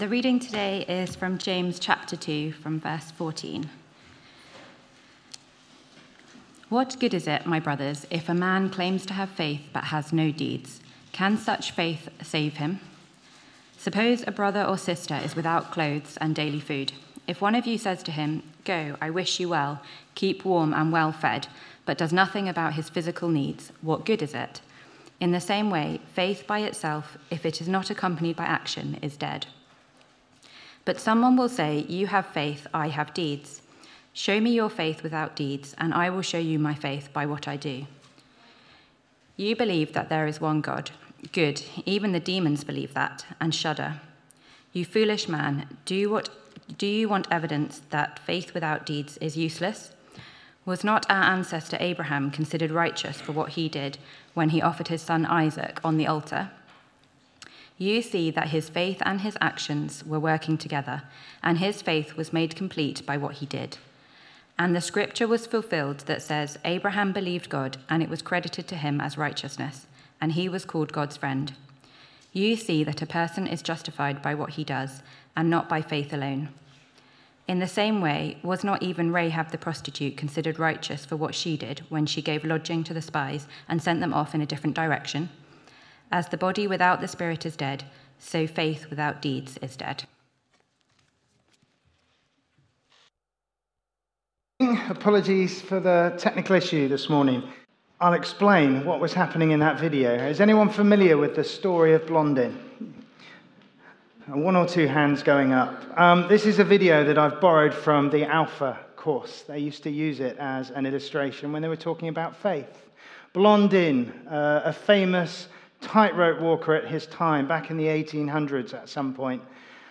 Media Library Media for Sunday Service on Sun 06th Jul 2025 10:00 Speaker